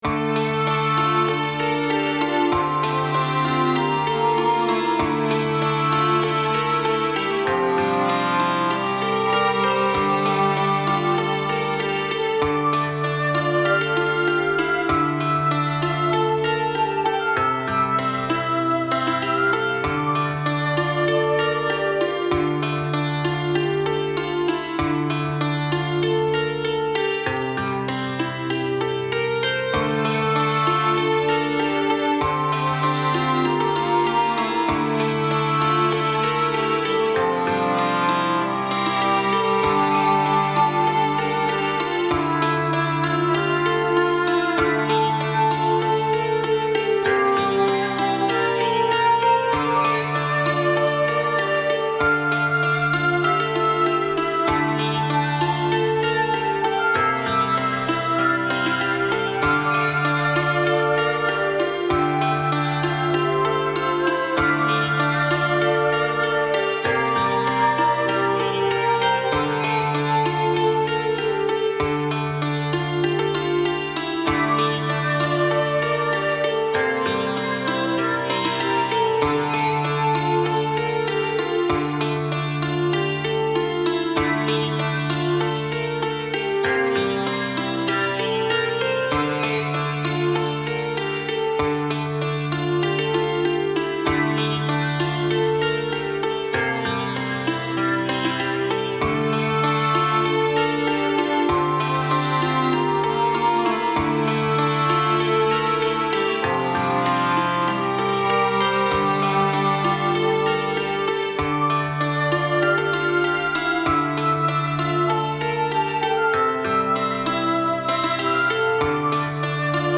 Another track for an RPG (I love RPG's if you couldn't already tell lol). Great for a mysterious forgotten island or even good Inn music!